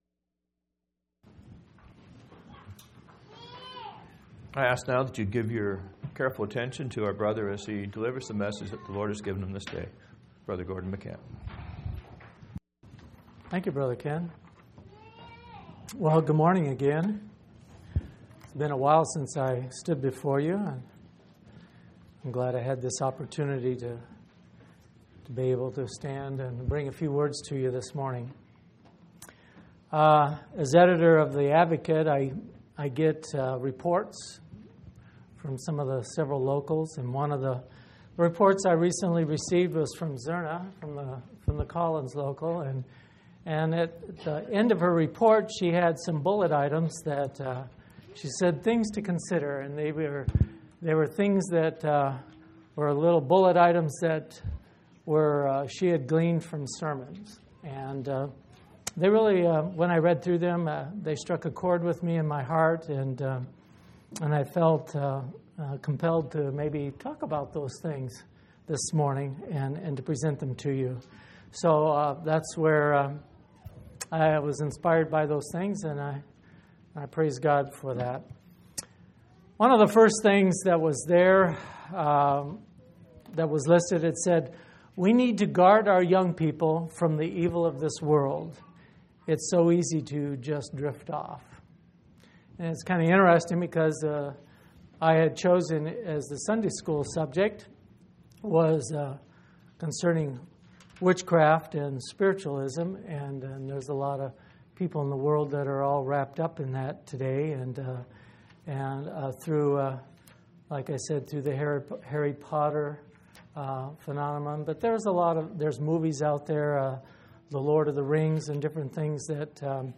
7/24/2005 Location: Phoenix Local Event